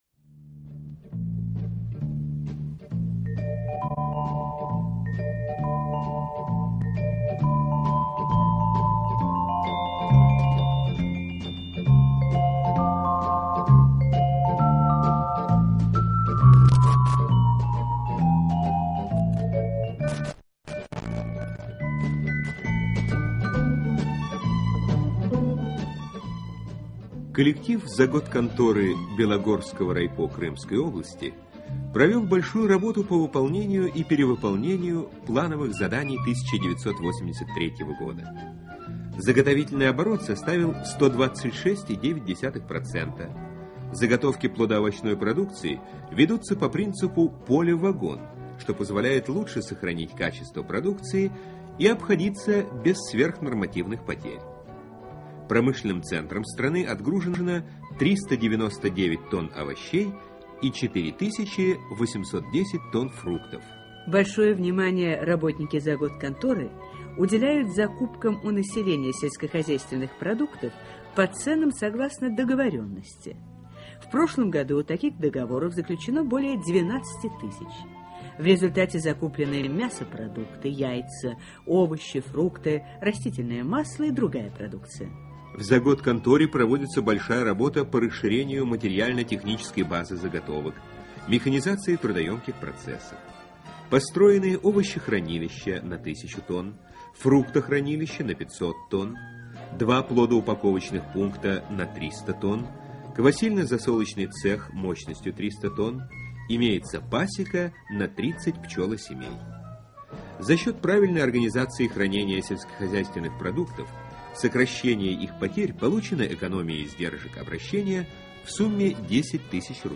Радиоузел ВДНХ. Такие сюжеты транслировались из динамиков, расположенных на столбах по всей территории выставки.
Мелодия, которая открывает передачу это пьеса Якова Дубравина Весёлая прогулка.
Дикторы, читающие текст, определены.